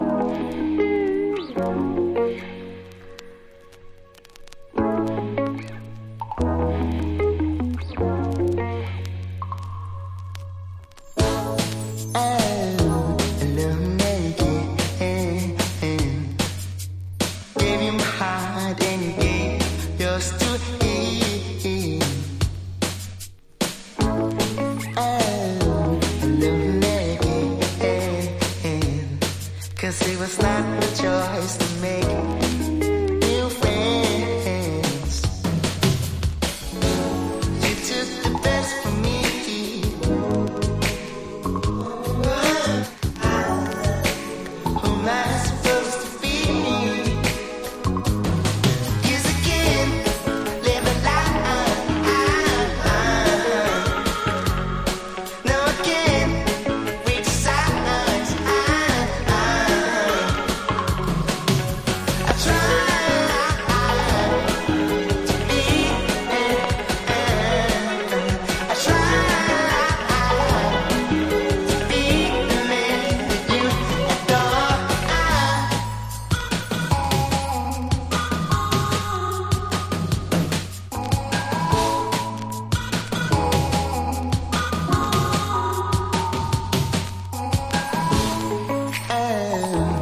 シンセポップやネオソウルを取り入れた極上のインディー・ポップ・ナンバー
INDIE DANCE